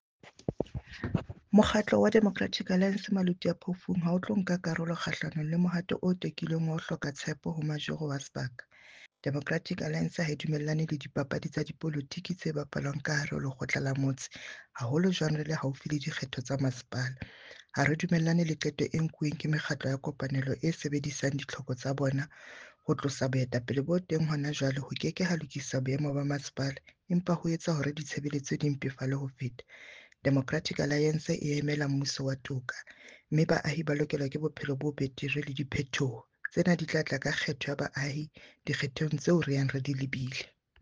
Sesotho soundbite by Cllr Ana Motaung.